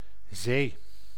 Ääntäminen
Synonyymit océan plan d'eau Ääntäminen France (Paris): IPA: [yn mɛʁ] Tuntematon aksentti: IPA: /mɛʁ/ Haettu sana löytyi näillä lähdekielillä: ranska Käännös Ääninäyte Substantiivit 1. zee {f} 2. zout {n} Suku: f .